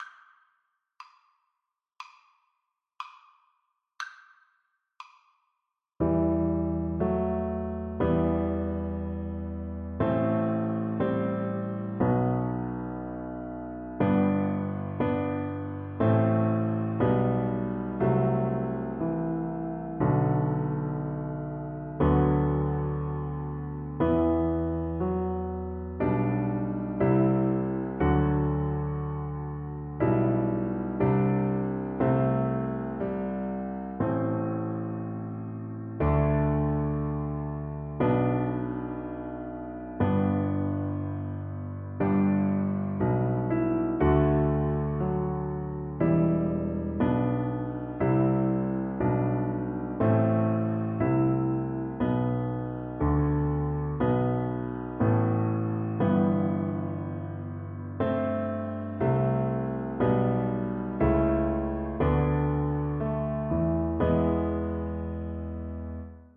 Christmas Christmas Cello Sheet Music And All in the Morning
Cello
Traditional Music of unknown author.
4/4 (View more 4/4 Music)
Gently Flowing
E minor (Sounding Pitch) (View more E minor Music for Cello )